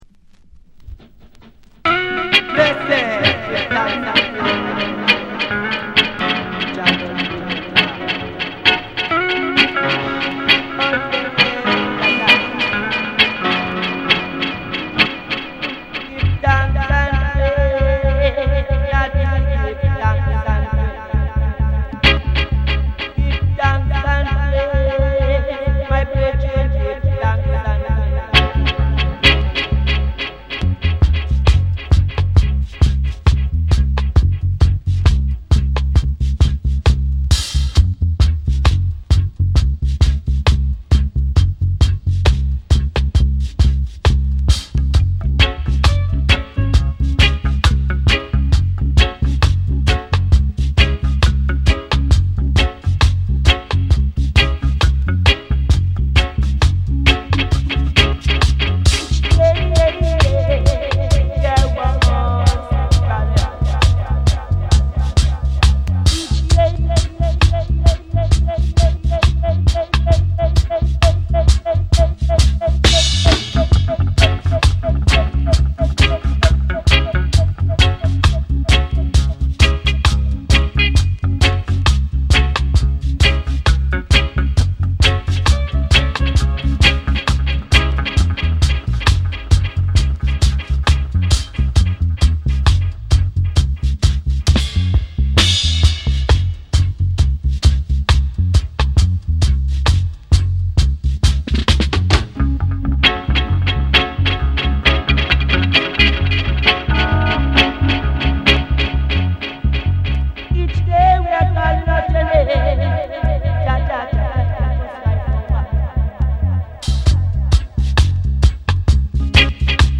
ダブステッパーズトロピカル